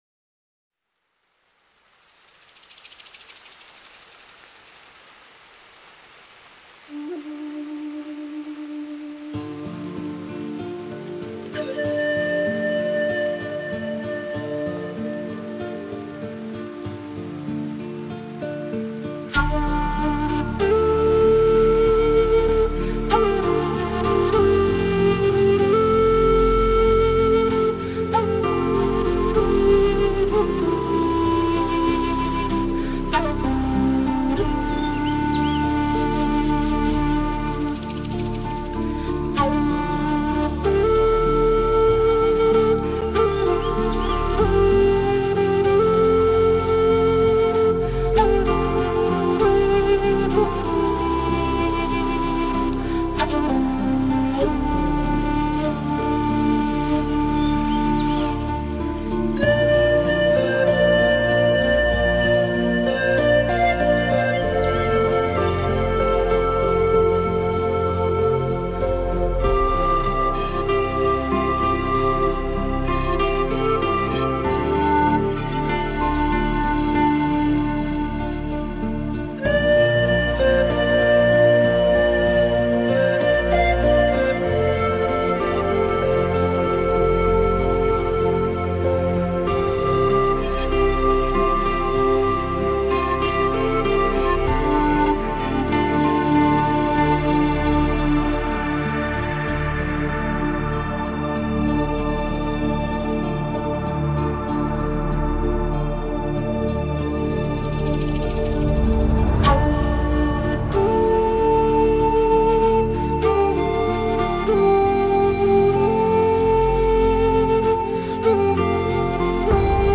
Genre: New Age, Folk, Native American, Panflute